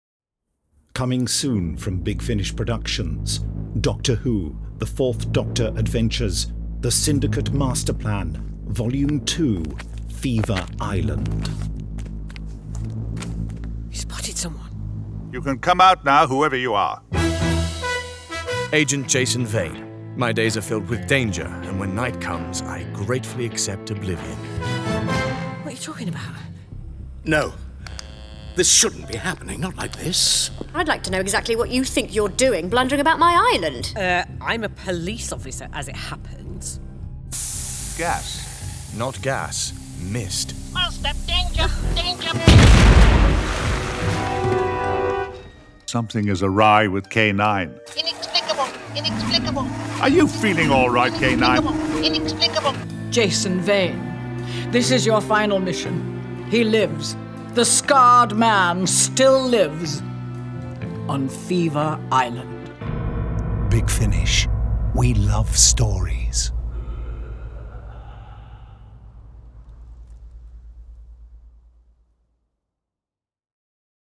full-cast original audio dramas